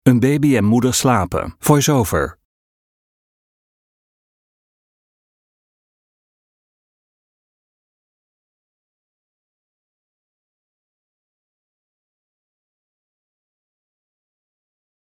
Gesnurk